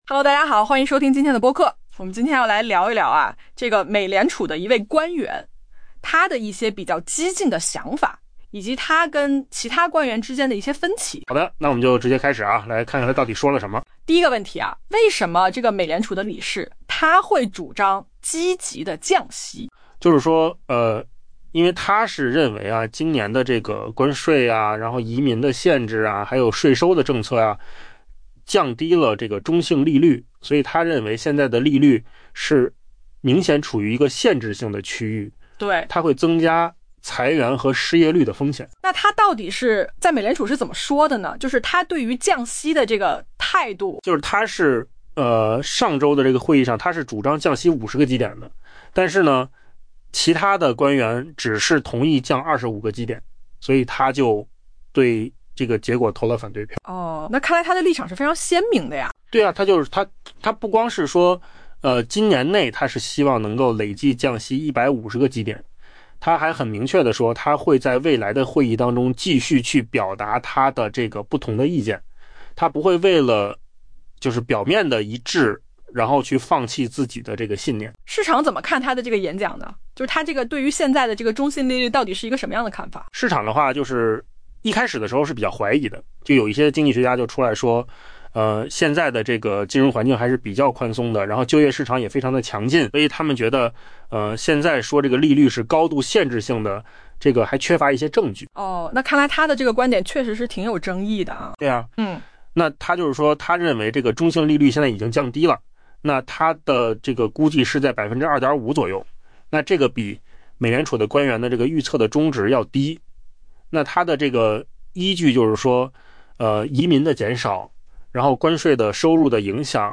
AI播客：换个方式听新闻 下载mp3
音频由扣子空间生成